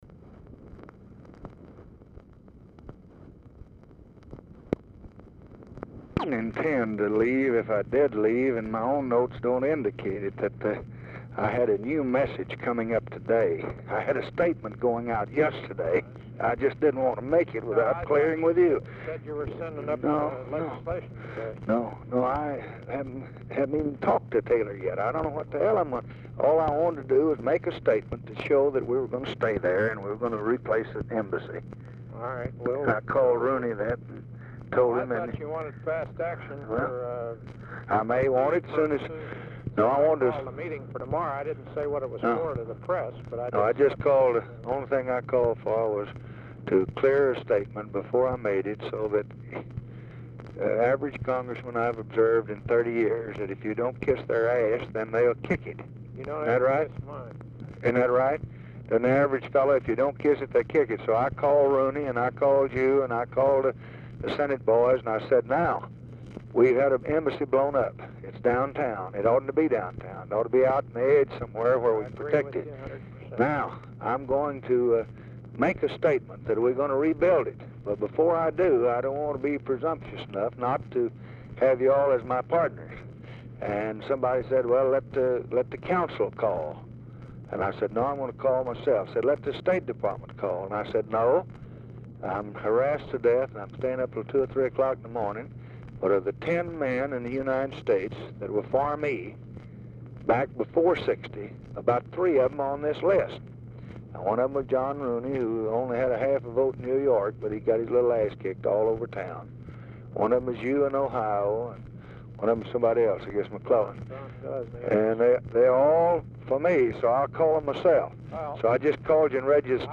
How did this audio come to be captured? RECORDING STARTS AFTER CONVERSATION HAS BEGUN Dictation belt Mansion, White House, Washington, DC